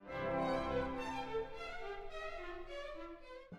third, which has a “skipping” sound, has no relationship to the rest of the music and appears to exist solely to irritate the singers (and make Cajetan chuckle into his chasuble?).
KV262-Kyrie-03Skip.wav